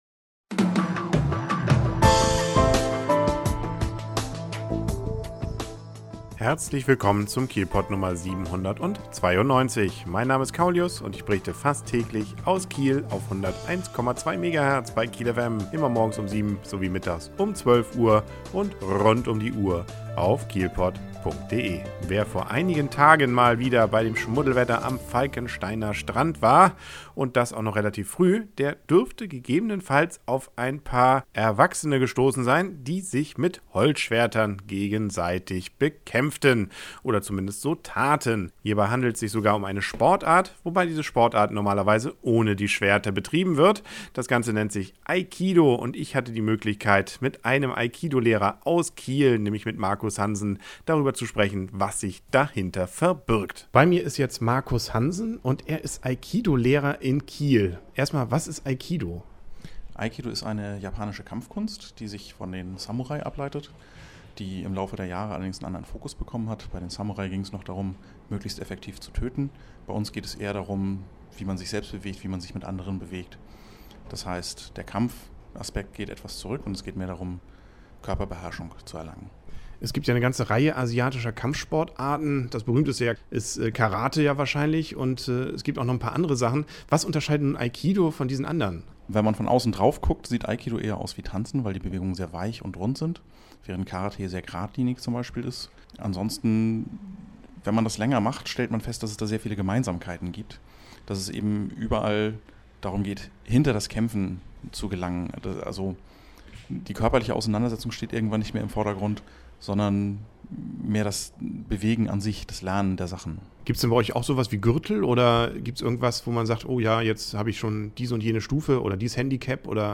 KielPod Ausgabe 792: Schwertkämpfer am Falckensteiner Strand und Aikido Interview